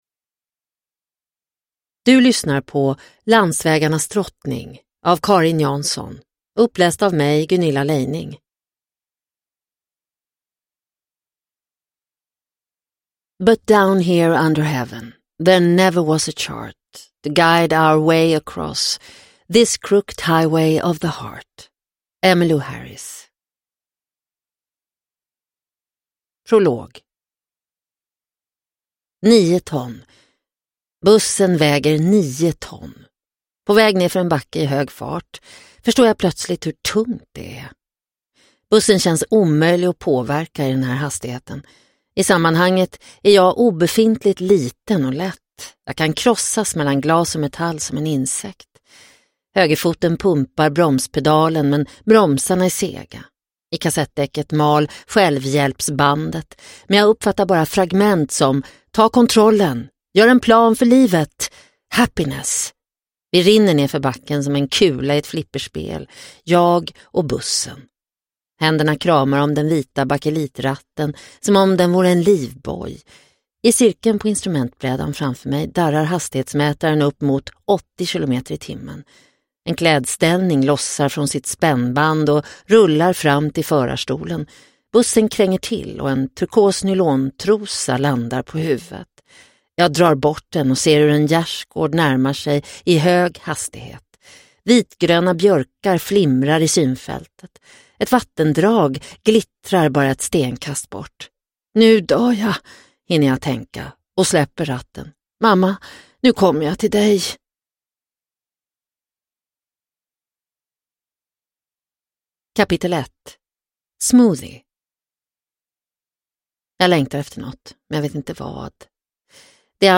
Landsvägarnas drottning – Ljudbok – Laddas ner